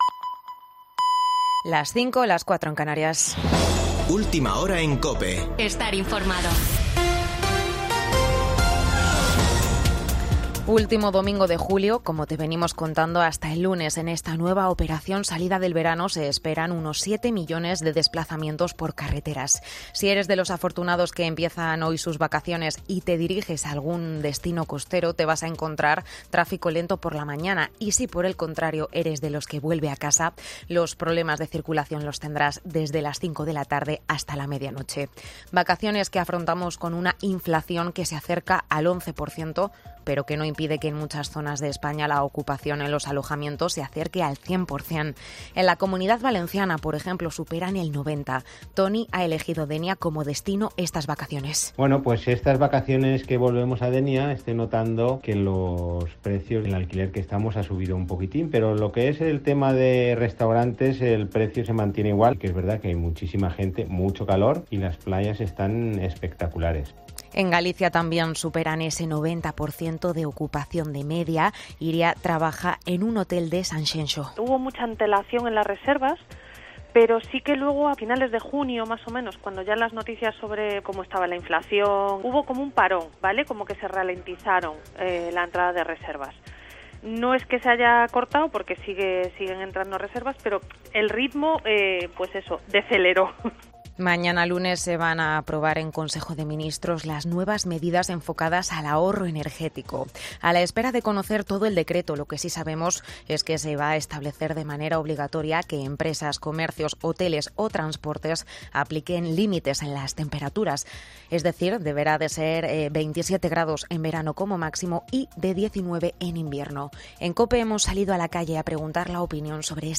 Boletín de noticias de COPE del 31 de julio de 2022 a las 05:00 horas